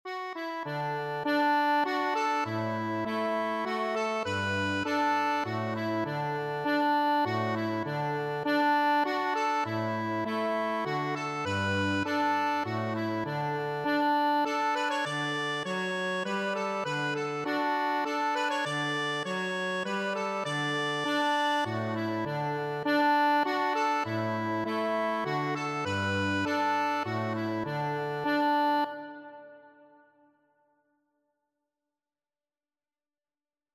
D major (Sounding Pitch) (View more D major Music for Accordion )
3/4 (View more 3/4 Music)
D5-D6
Accordion  (View more Easy Accordion Music)
Traditional (View more Traditional Accordion Music)
come_thou_fount_ACC.mp3